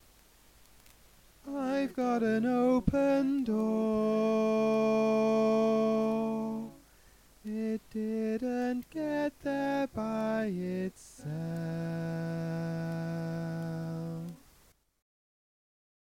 Key written in: A Major
Type: Barbershop